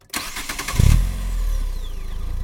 Спокойный звук быстрого запуска двигателя